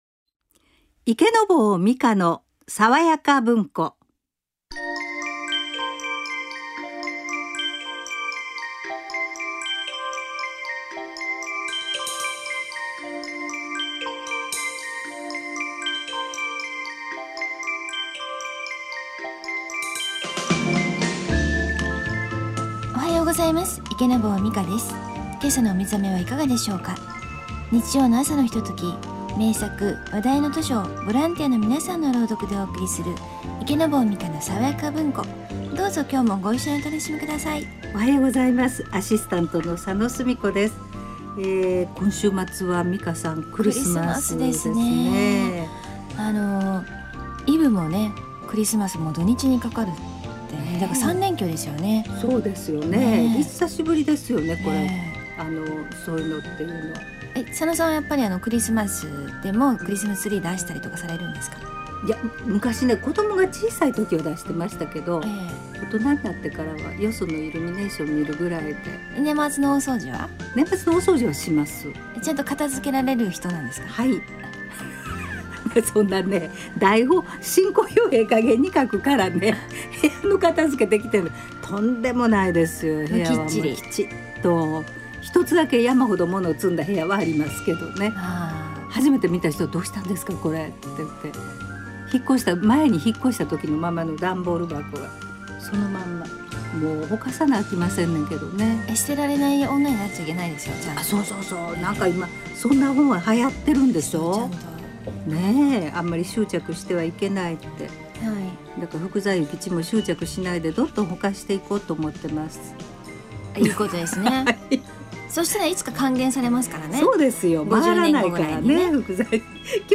今回は、ここでしか聴けない、楽器のお話と、「獅子」をモチーフにした演奏を聴かせて頂きました。